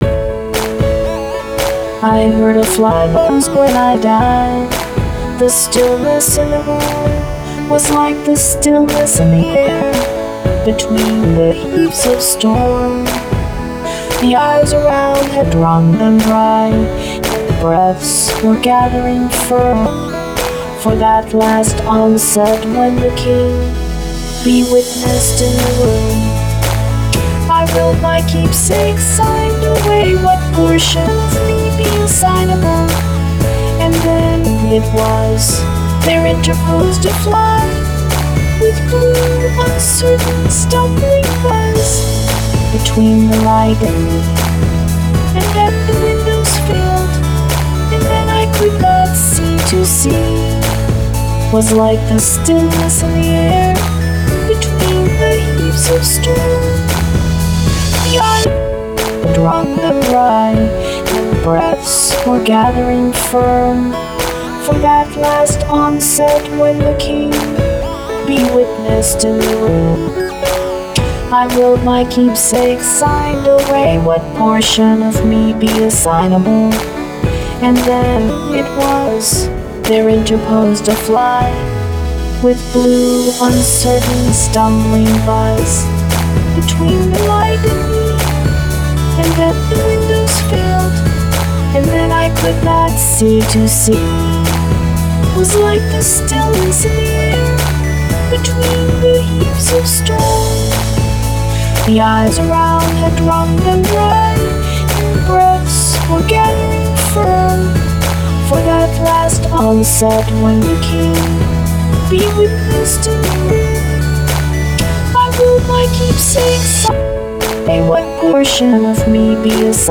Autotune Poetry: I Heard a Fly Buzz When I Died by Emily Dickinson